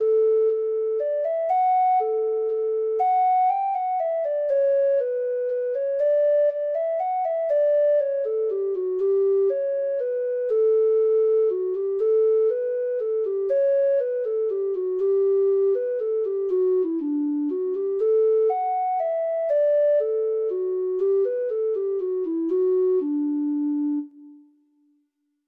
Free Sheet music for Treble Clef Instrument
Irish